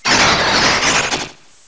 pokeemerald / sound / direct_sound_samples / cries / uncomp_falinks.aif